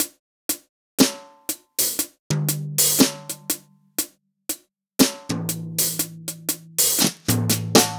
Unison Jazz - 2 - 120bpm - Tops.wav